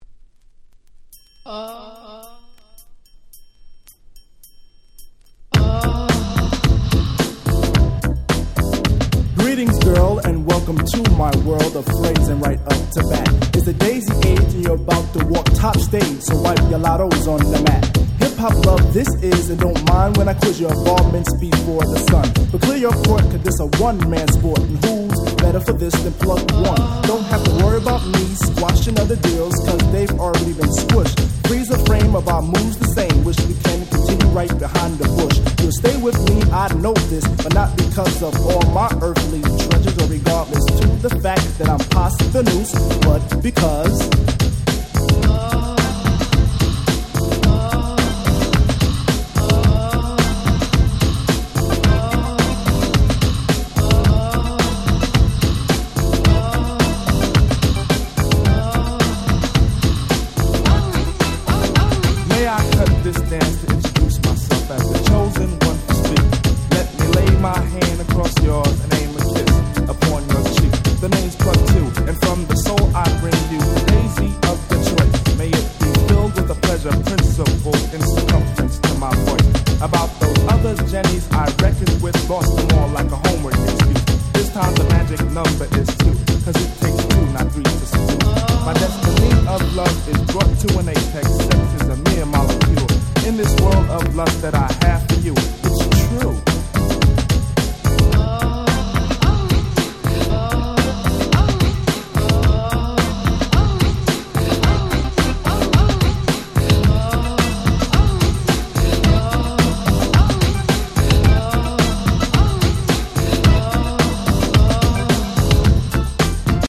89' Smash Hit Hip Hop !!